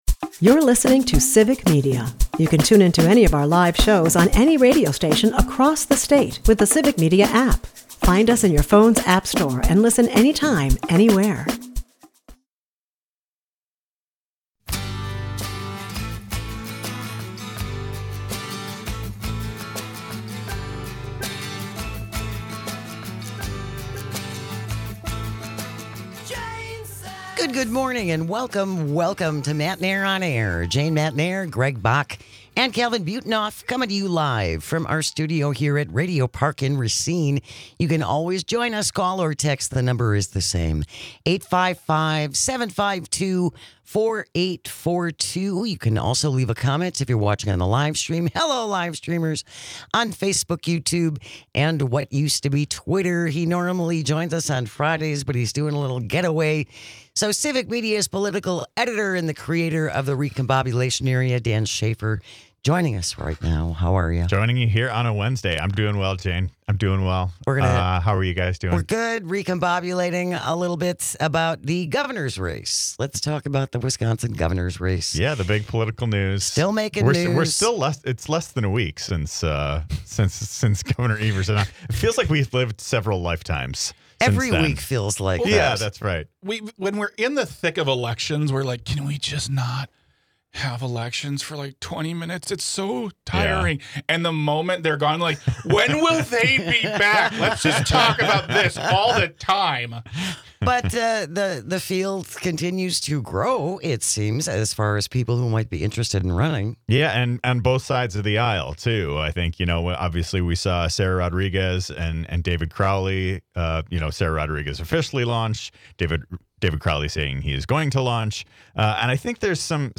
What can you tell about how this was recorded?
As a treat, we present a curated collection of our favorite moments from this week's show, enjoy! Matenaer On Air is a part of the Civic Media radio network and airs weekday mornings from 9-11 across the state.